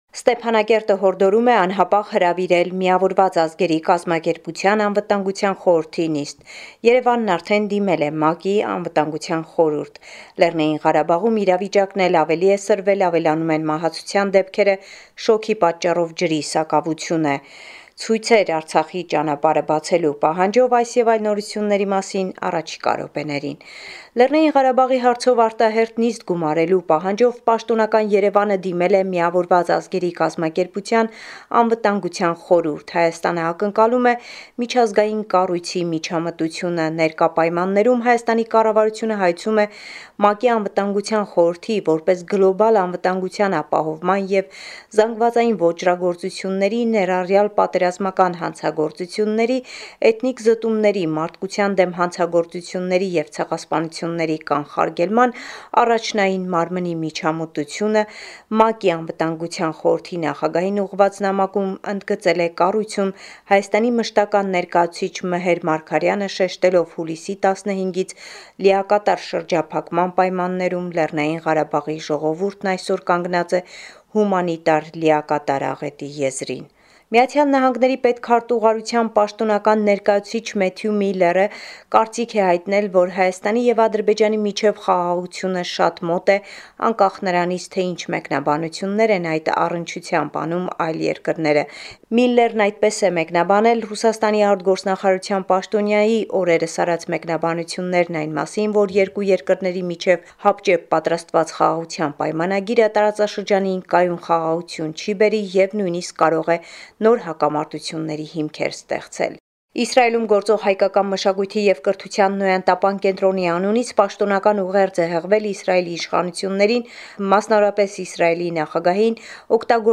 Latest news from Armenia, Artsakh and the Diaspora